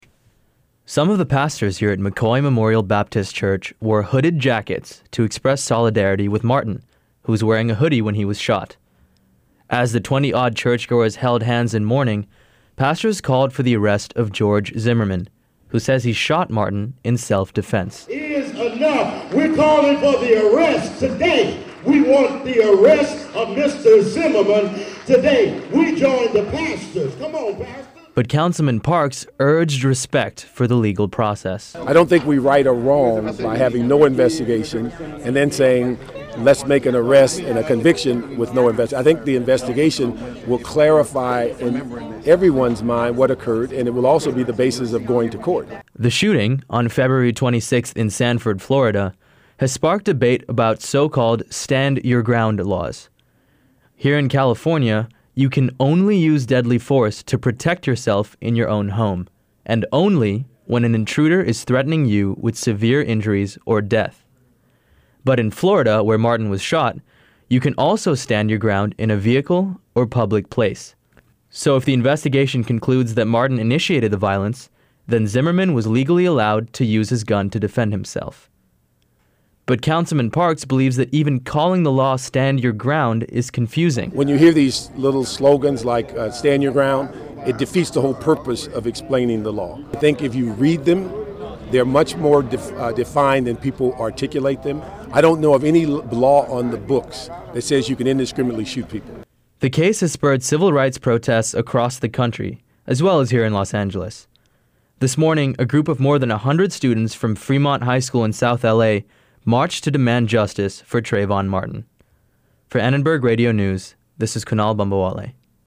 But Councilman Parks urged respect for the legal process.